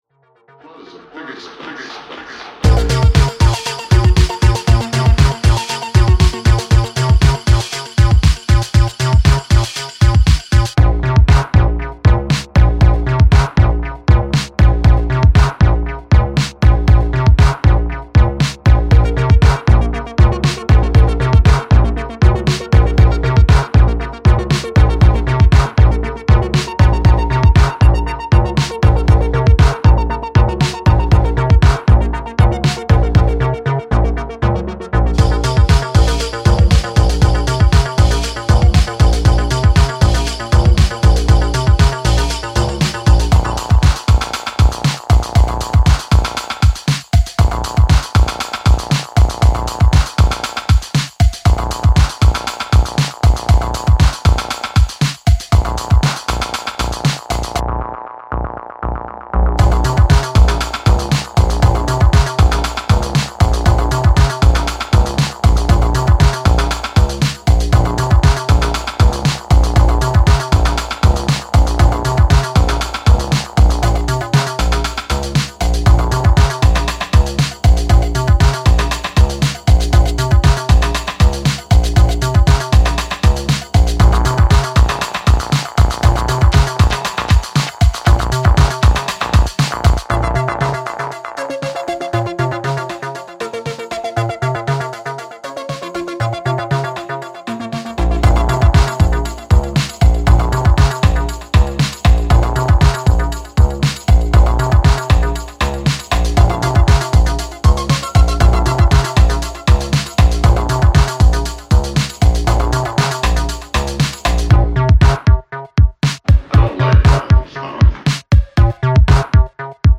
音程怪しくふらつくシンセのフリーキーさがたまらない変態エレクトロ